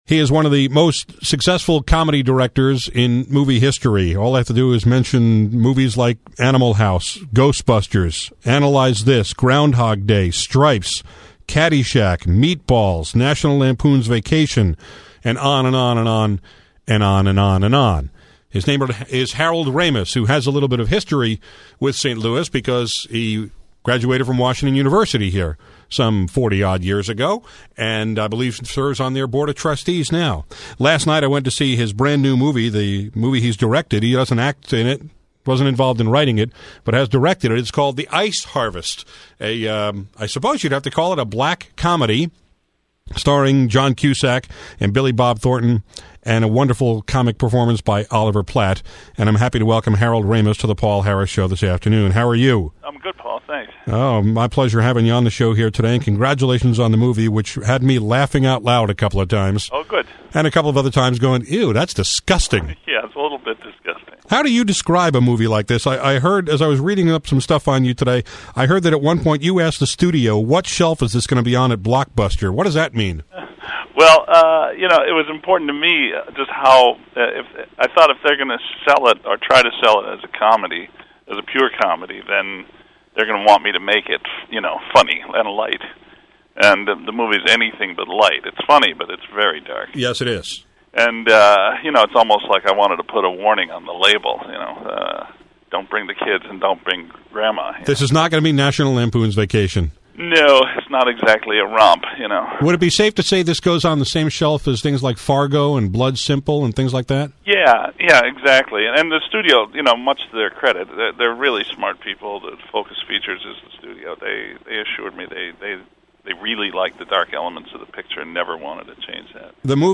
So I dug into my archives to find an interview I did with him about his then-new movie, “The Ice Harvest,” starring John Cusack and Billy Bob Thornton.